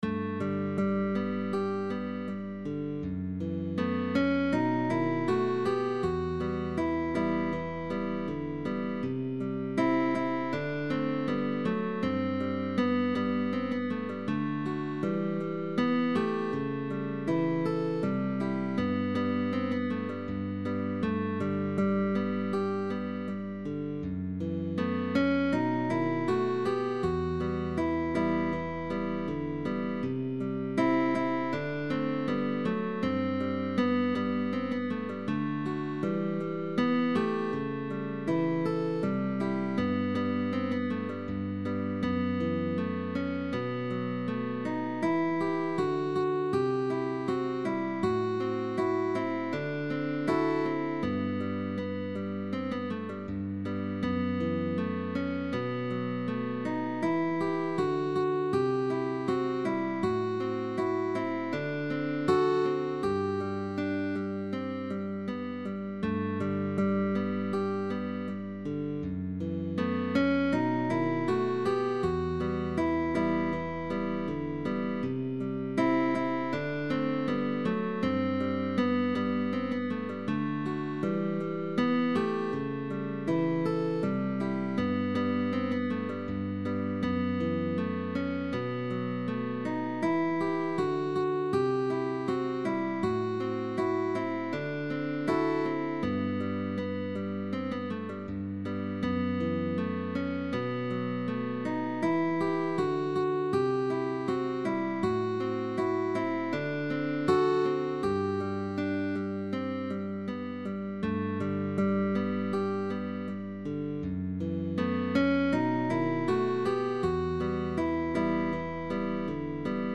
GUITARRA MELÓDICA. DÚO: ALUMNO Y PROFESOR
arreglada para dos guitarras. (Alumno y profesor).